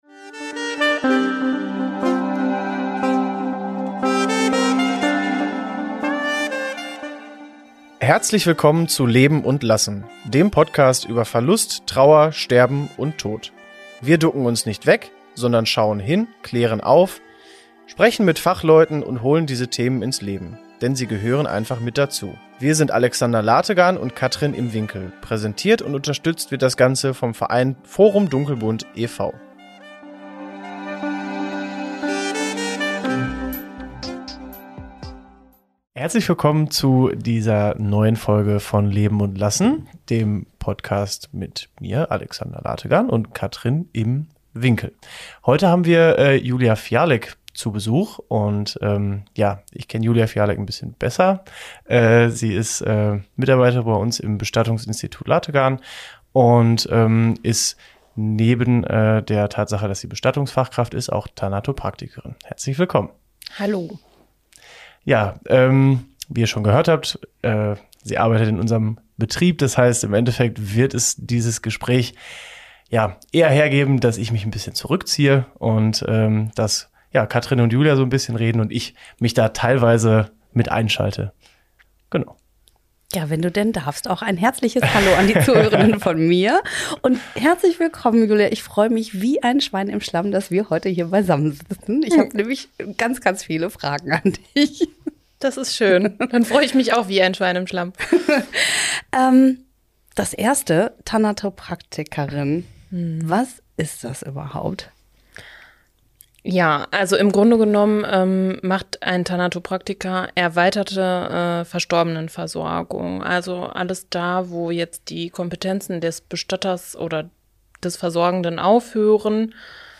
Unsere heutige Interviewpartnerin